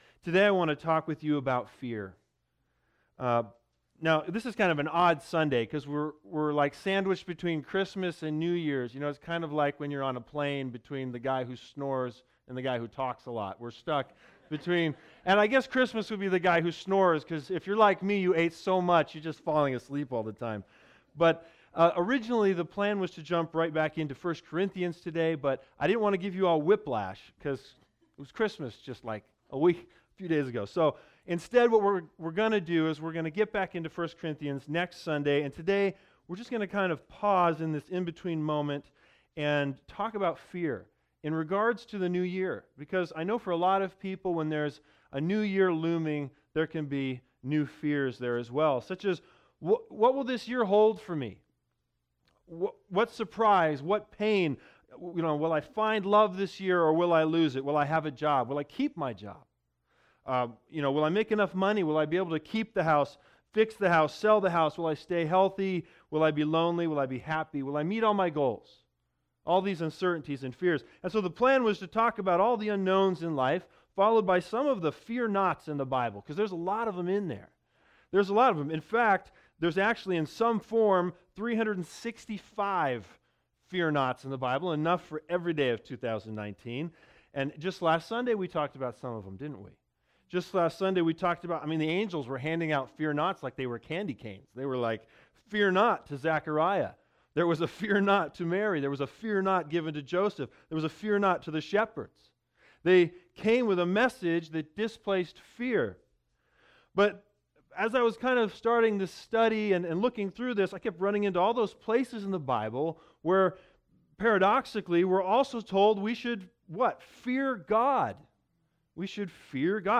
Special Sermon | Listen to this special New Year’s message to understand the Fear of the Lord and our responses to it.